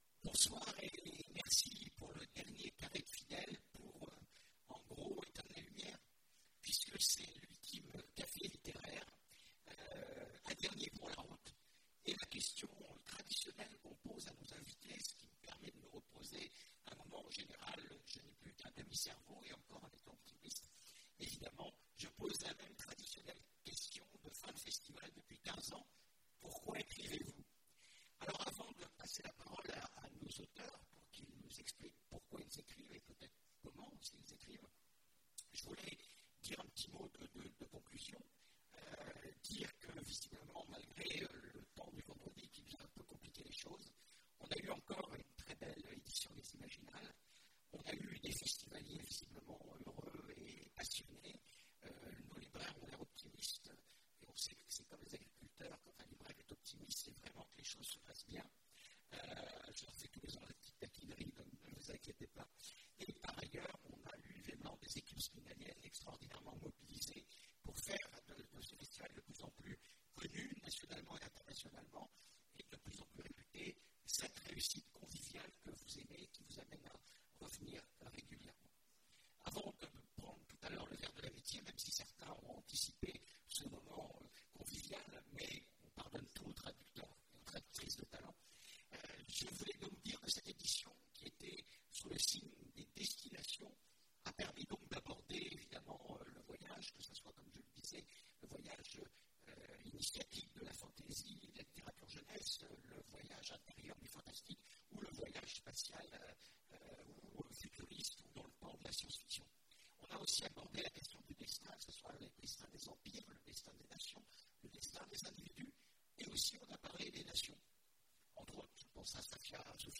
Imaginales 2017 : Conférence Un dernier pour la route ! Pourquoi être écrivain ?